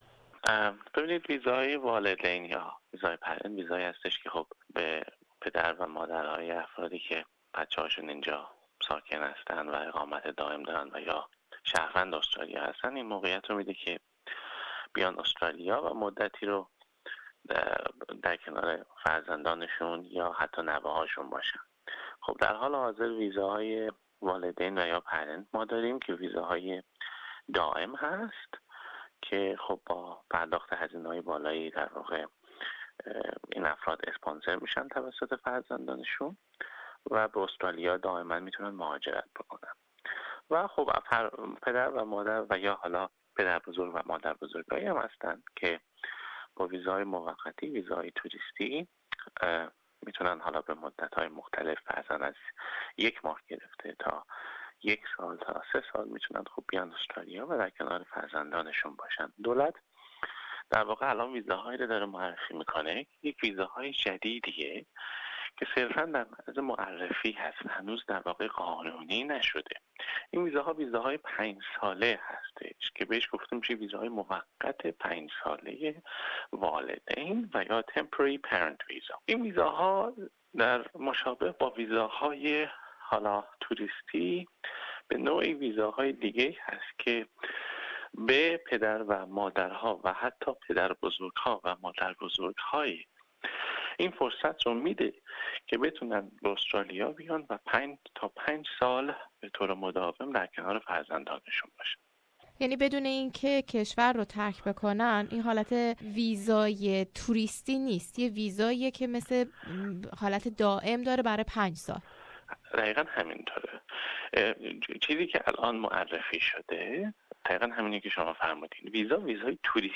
مصاحبه